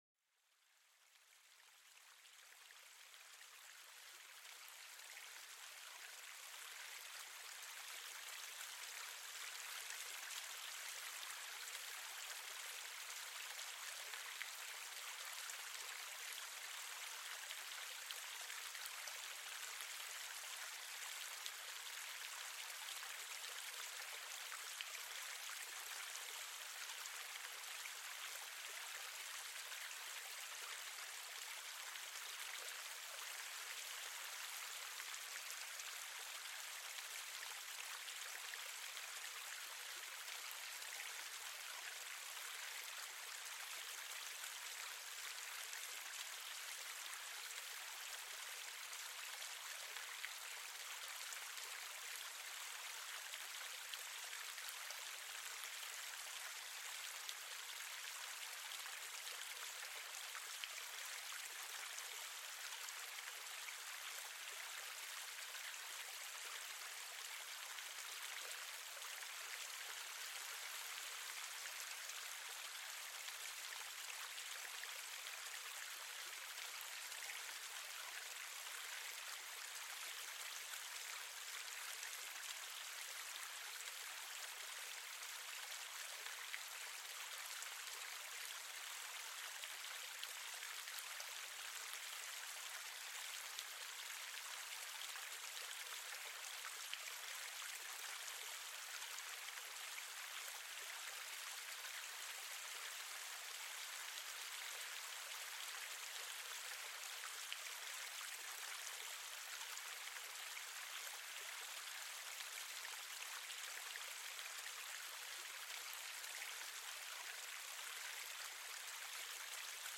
SONIDOS DE LA NATURALEZA PARA LA RELAJACIÓN